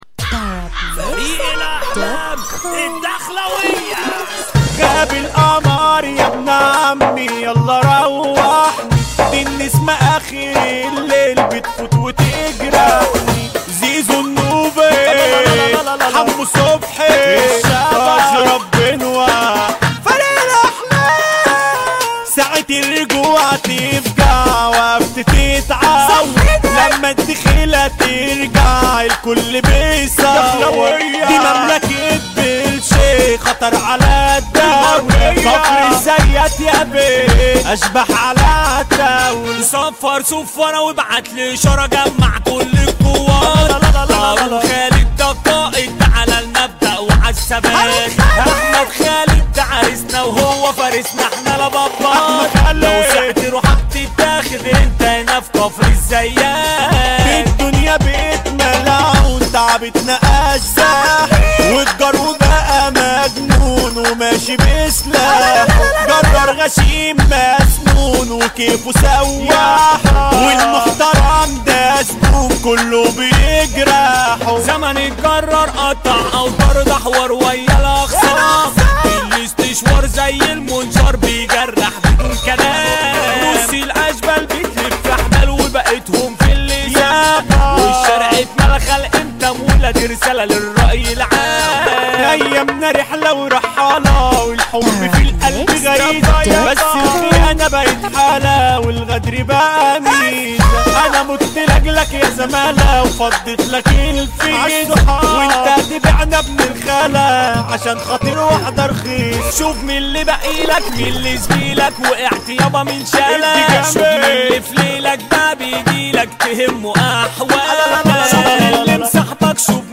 • النوع : festival